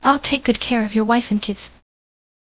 segment_12_voiceover.wav